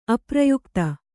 ♪ aprayukta